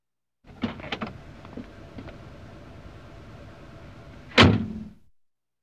Truck Door Open And Close Door Sound
transport
Truck Door Open And Close Door